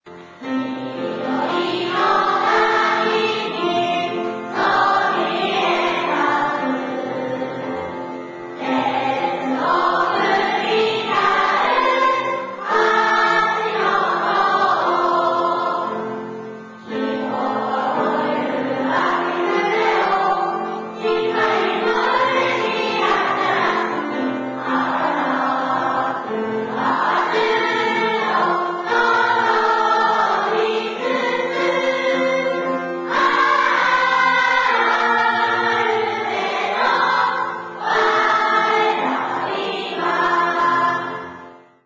amarubesyougakou_kouka-2.wma